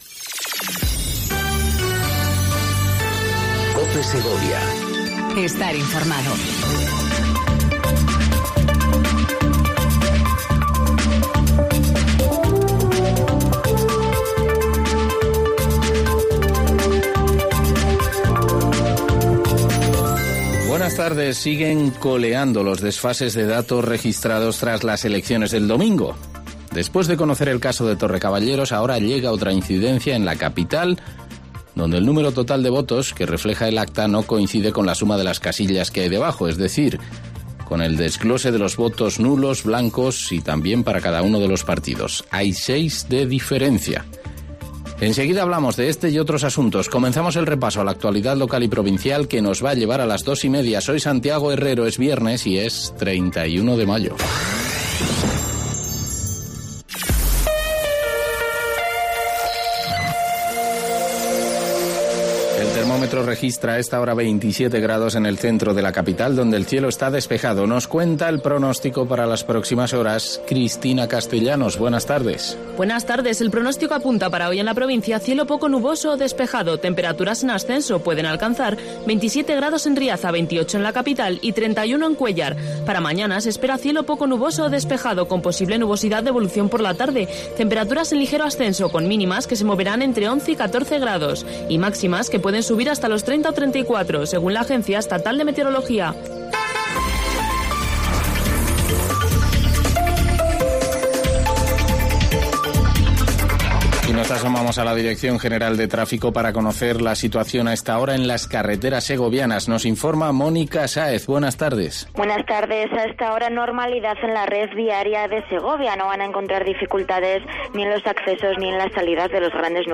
INFORMATIVO DEL MEDIODÍA EN COPE SEGOVIA 14:20 DEL 31/05/19